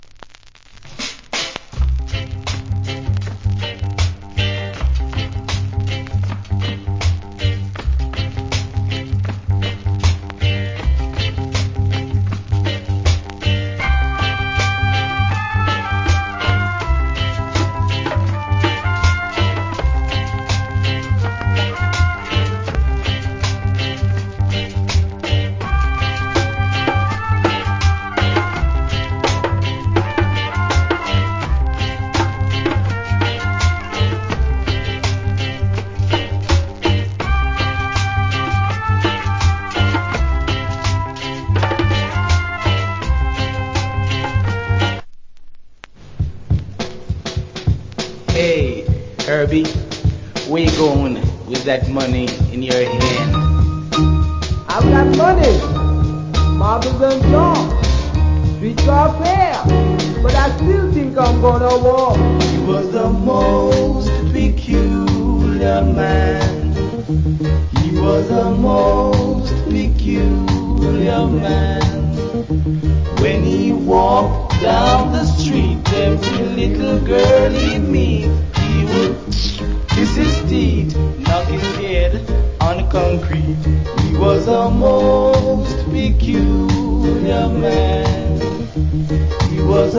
Nice Rock Steady Inst.